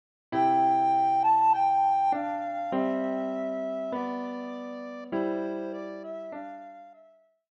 deutsches Weihnachtslied